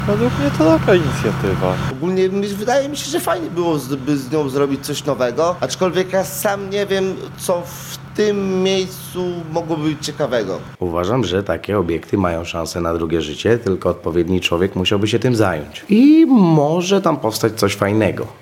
Pytani przez nas ostrołęczanie chcą, by wieża była znów wykorzystywana.